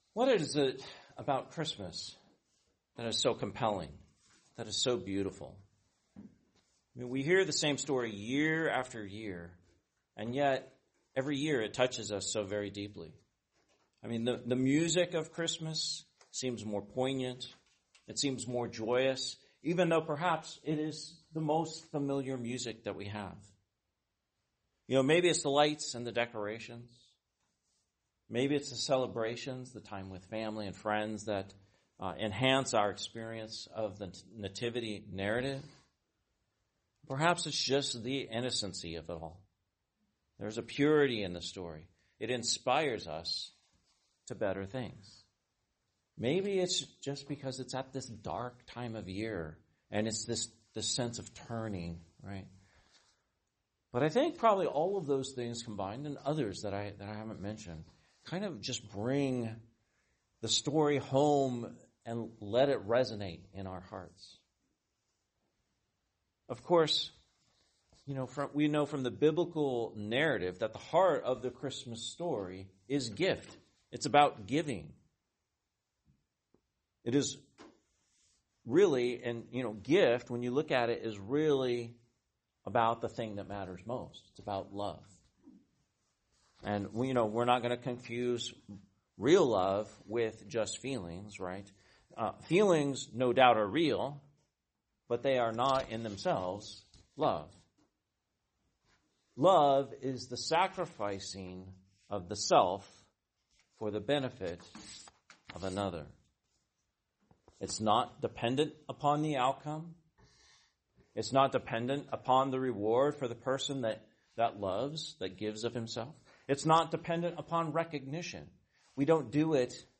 Homily, Christmas Eve, 2025